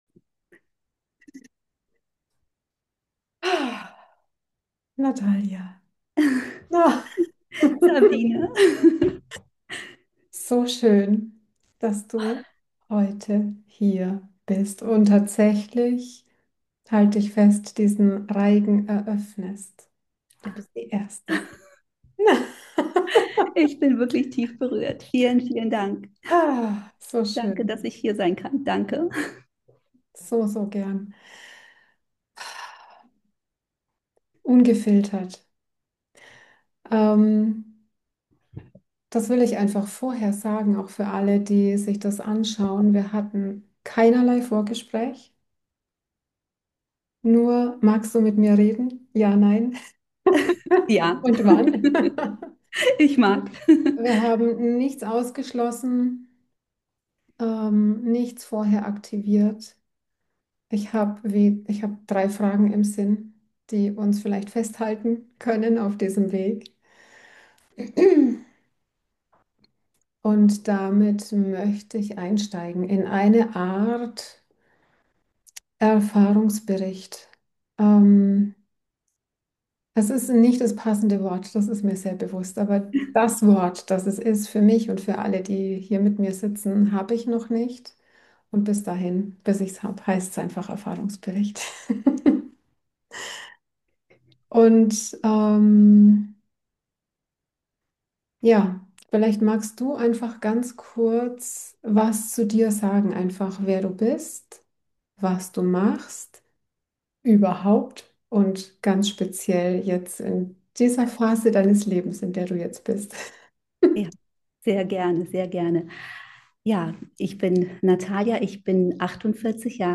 ein Gespräch unter Schwestern